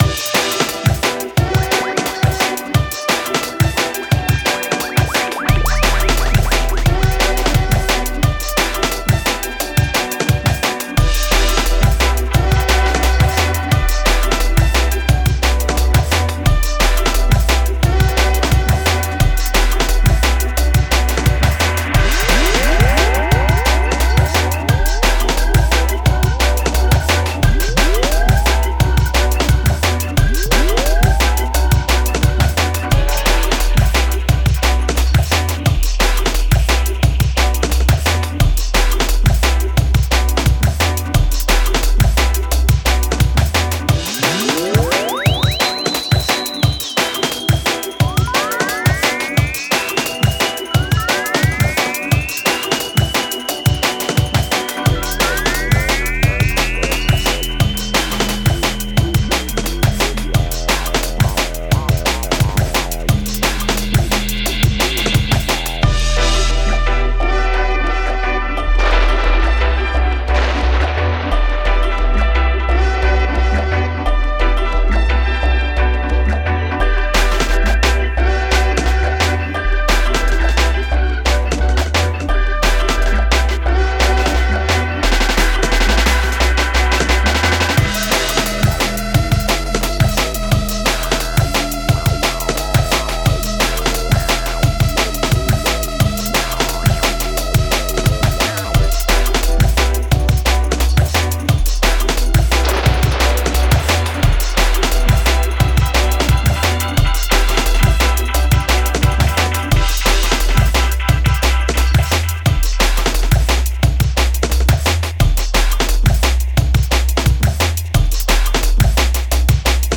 Genre:Jungle
デモサウンドはコチラ↓